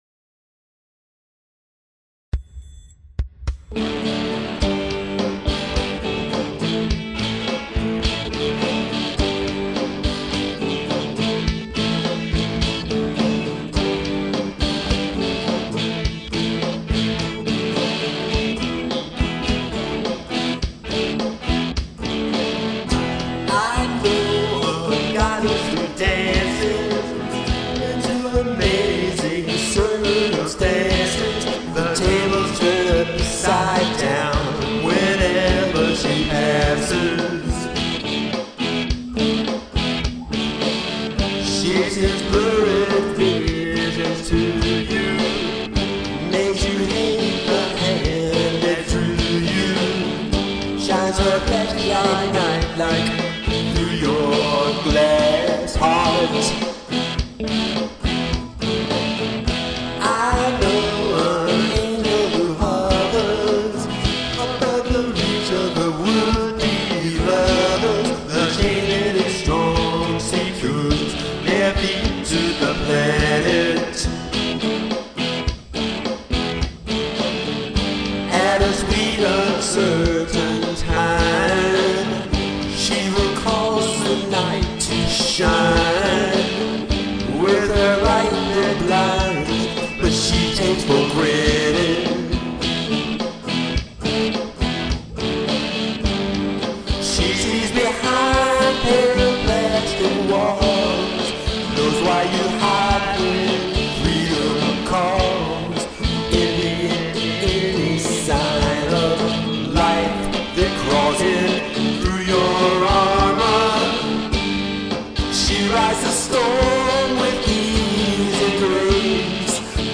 stereo, 1.56MB(mp3)